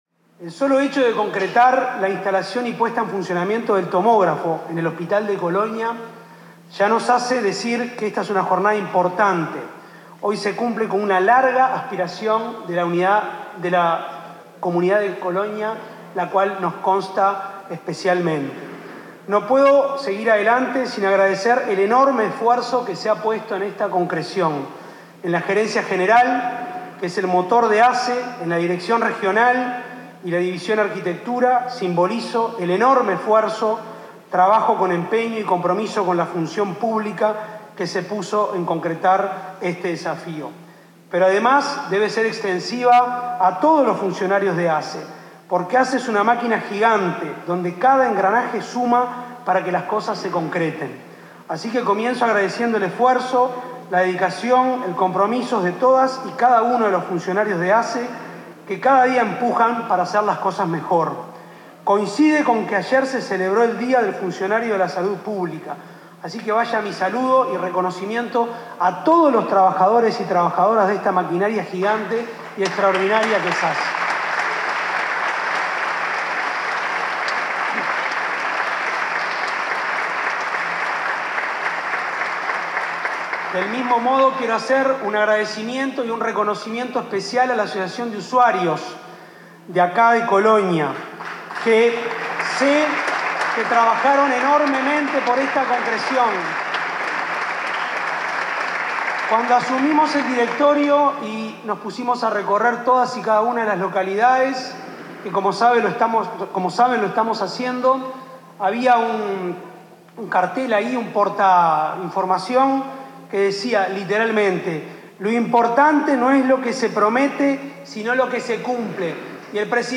Palabras de Cristina Lustemberg y Álvaro Danza en Colonia 21/10/2025 Compartir Facebook X Copiar enlace WhatsApp LinkedIn La ministra de Salud Pública, Cristina Lustemberg, y el presidente de la Administración de los Servicios de Salud del Estado (ASSE), Álvaro Danza, se expresaron en la inauguración del tomógrafo en el hospital de Colonia.